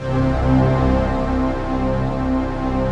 SI1 CHIME01R.wav